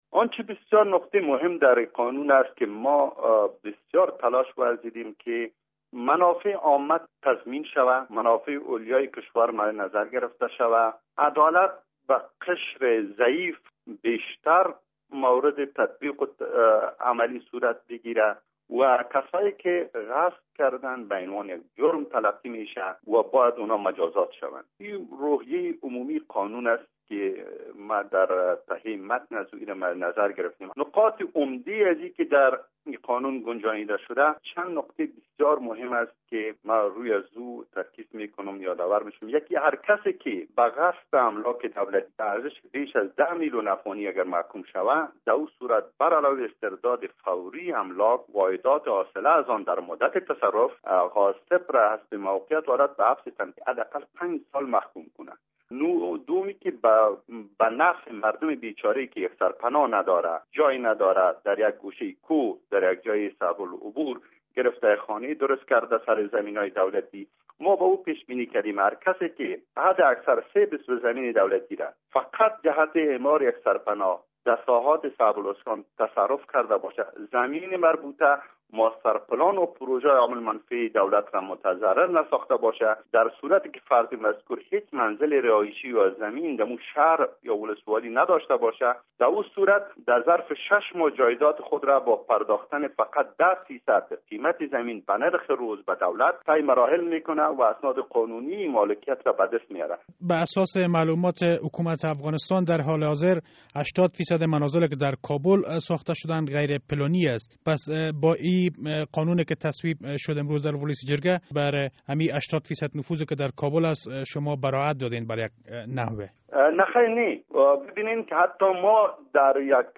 مصاحبه در مورد قانون جلوگیری از غصب زمین های دولتی و غیر دولتی